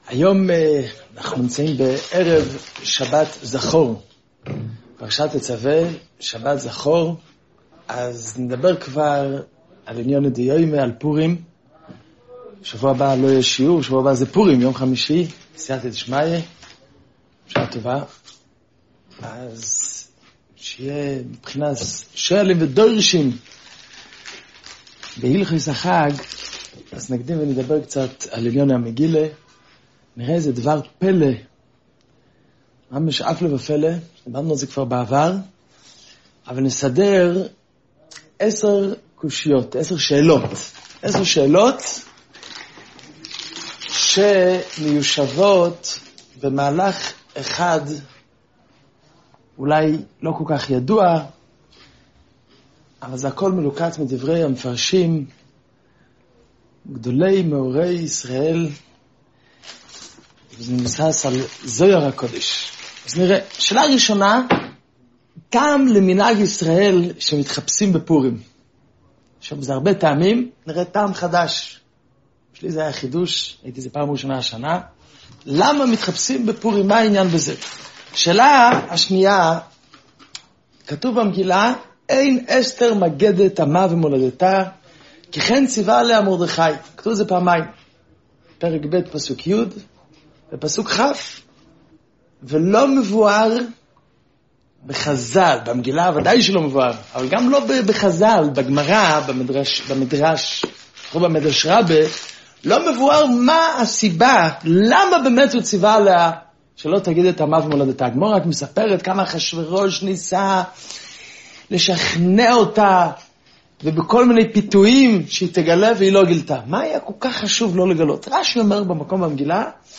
שיעור לקראת פורים תשעח – בדברי הזוהר שאסתר הכניסה שידה במקומה